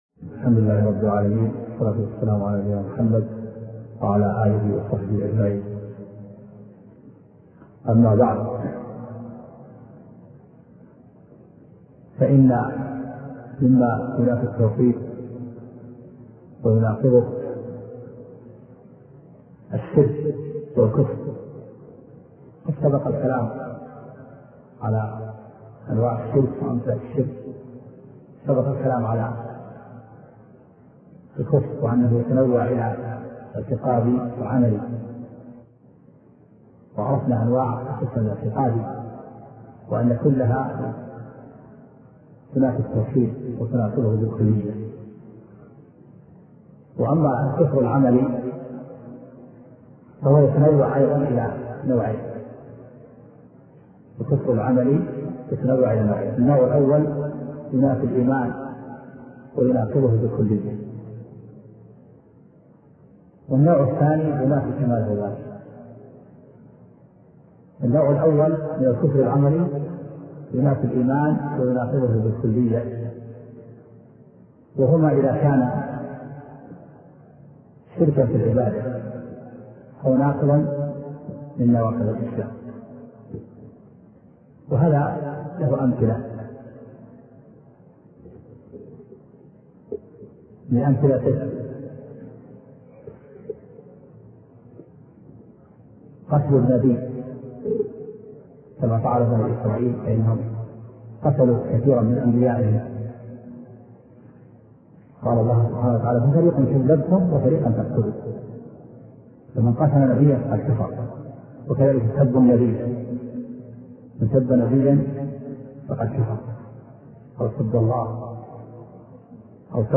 أرشيف الإسلام - ~ أرشيف صوتي لدروس وخطب ومحاضرات الشيخ عبد العزيز بن عبد الله الراجحي
دروس في العقيدة [1]